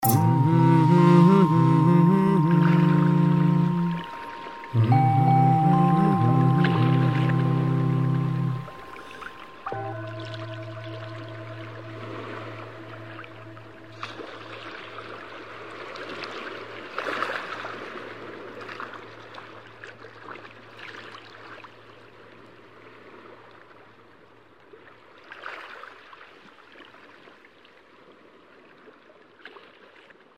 bell and sea